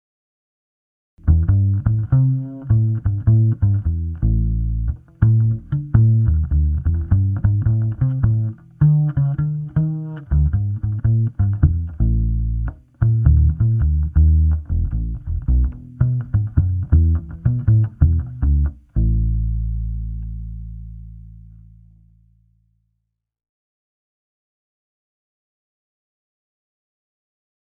Bugbass
ai_ten2_bugbass.mp3